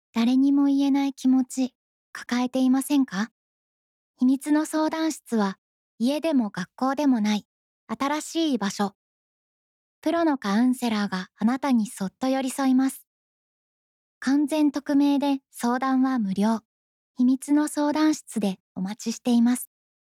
やわらかさ、透明感、温かみのある声です。
ウィスパー、ささやき